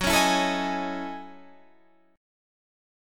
F#m13 chord